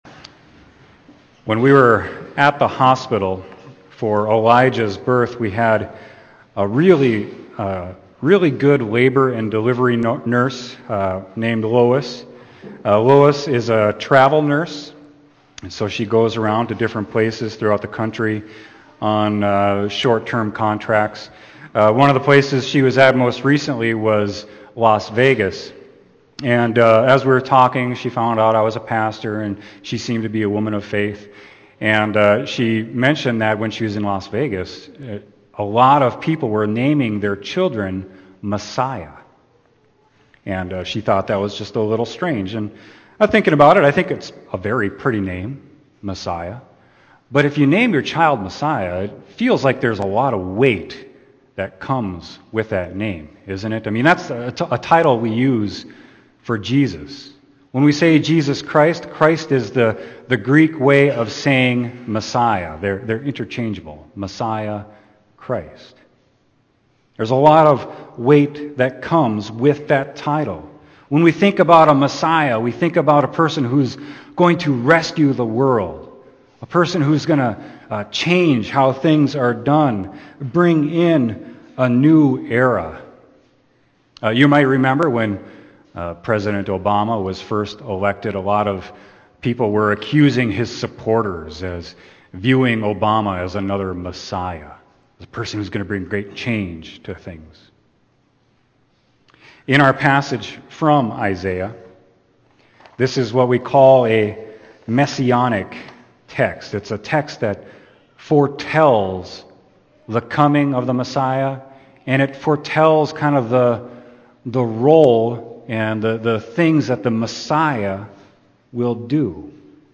Sermon: John 8.12-30